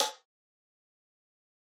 Outside Snare.wav